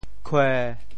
潮语发音
kuê1